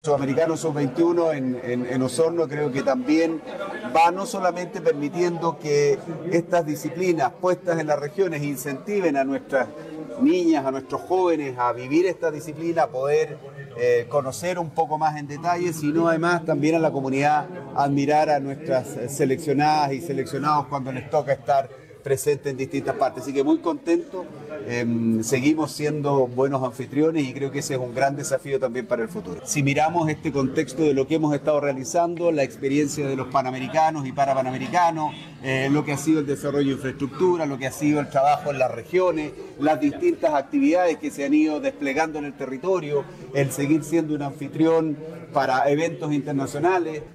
Este tipo de eventos en regiones, permitirá que niños y jóvenes puedan interiorizarse de otros deportes y poder fomentar la práctica del voleibol, entre otras disciplinas como explicó el Ministro Jaime Pizarro.